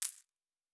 Coin 02.wav